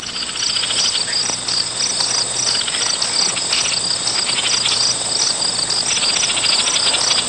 Birds And Insects Sound Effect
birds-and-insects.mp3